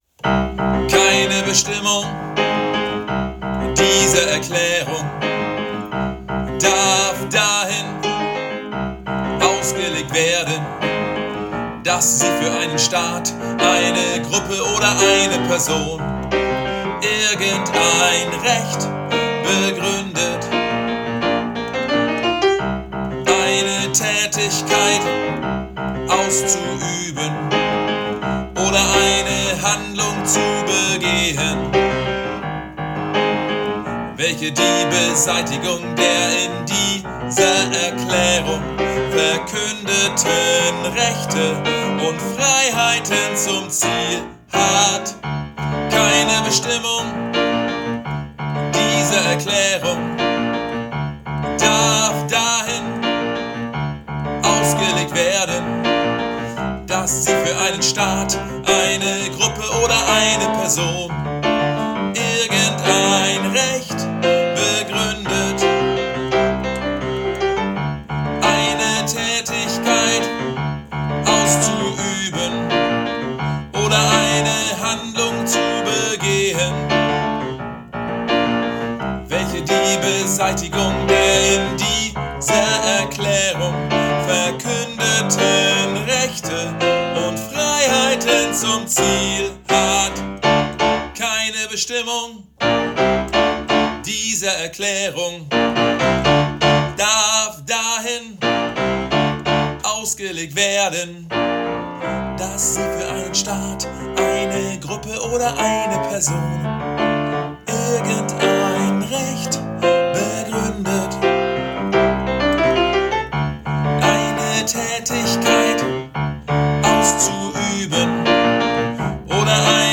Übe-Dateien
SOPRAN